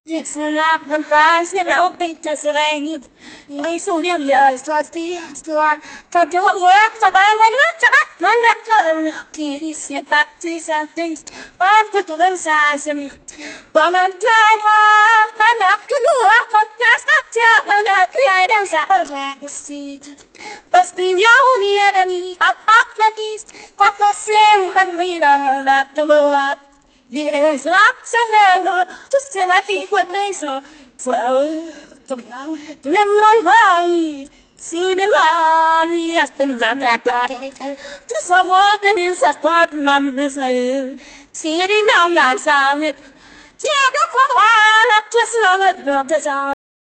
Trash can Rock band Ending live recording in 2002 0:28 punchline drum joke rimshot, live drum set, real, choked cymbal, bass drum, loud, realistic 0:03 Live vocals of a kpop girl group,singing,hitting high notes,realistic, human voice,and laughing and talking speaking korean and Japanese on instagram live 0:47
live-vocals-of-a-kpop-wrtxrdri.wav